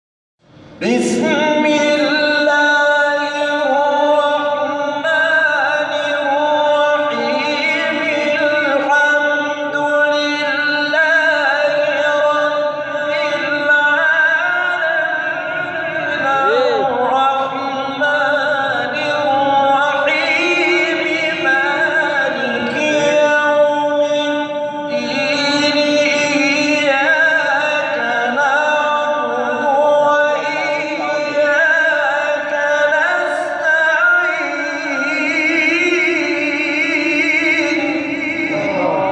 گروه شبکه اجتماعی: فرازهای صوتی از قاریان ممتاز و تعدادی از قاریان بین‌المللی کشورمان را می‌شنوید.